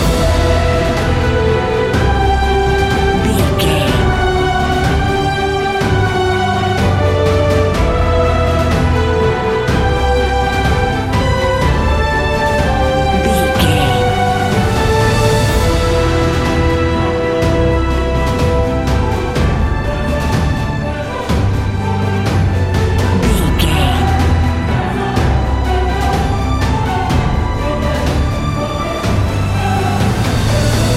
Uplifting
Aeolian/Minor
Slow
brass
choir
orchestra
percussion
strings